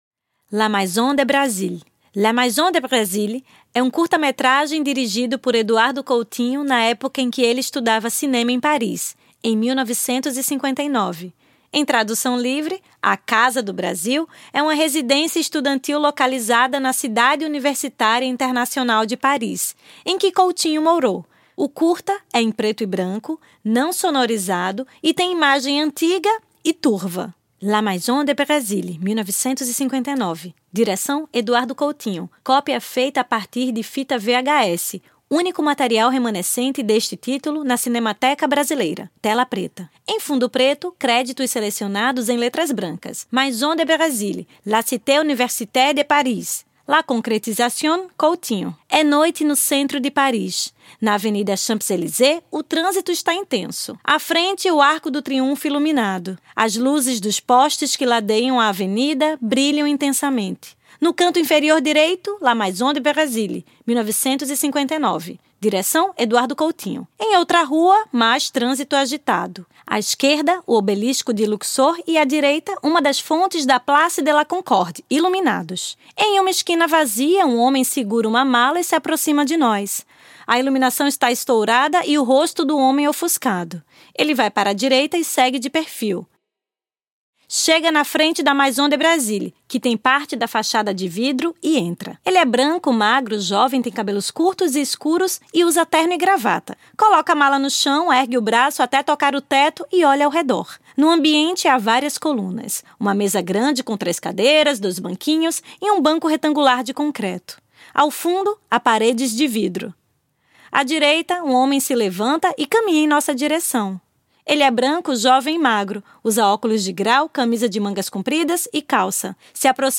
Ocupação Eduardo Coutinho - Audiodescrição | Estação 02 - Instituto Moreira Salles